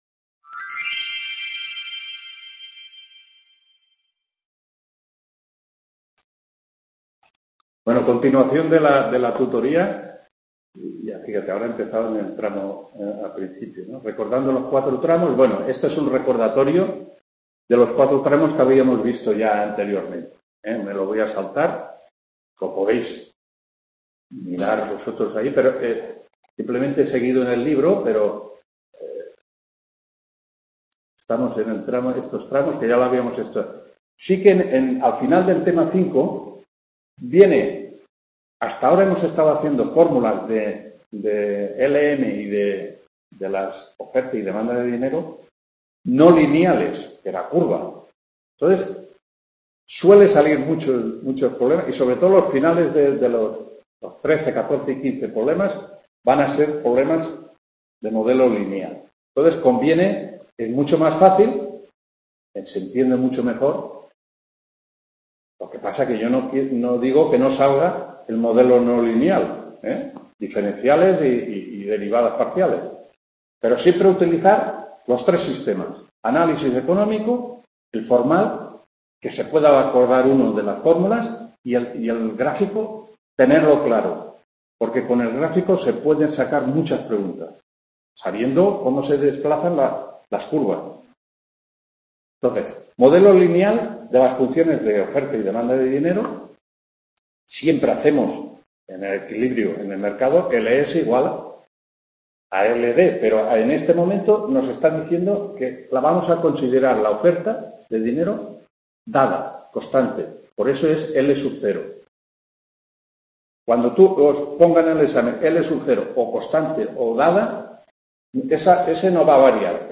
9ª TUTORÍA 2ª PARTE TEMA 6 (I) MODELO IS-LM ECONOMÍA…